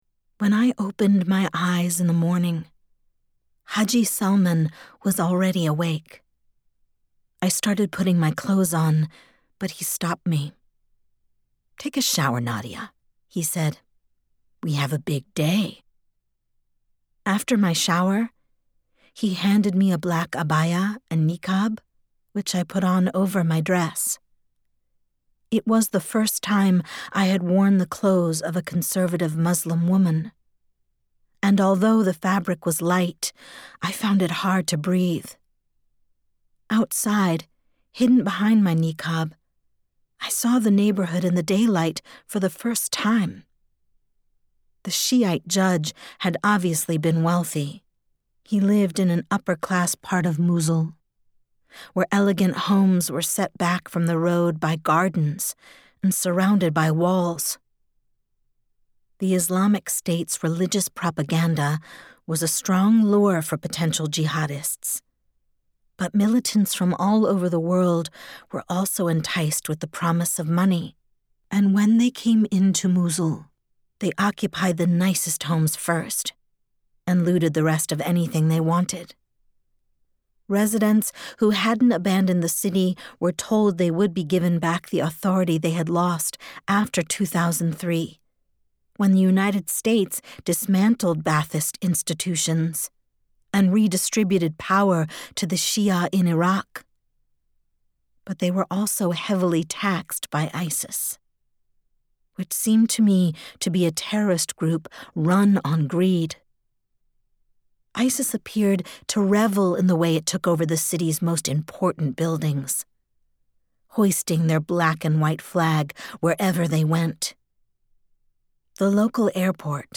Audiobook - Voice-Over Performer